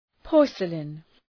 Προφορά
{‘pɔ:rsəlın}